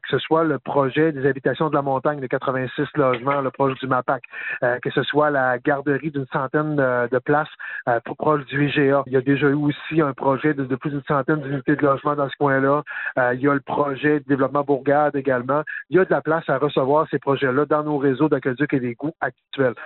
Daniel Côté précise cependant que les projets de développement de logements situés près du secteur de l’Hôtel de ville pourront aller de l’avant sans problème :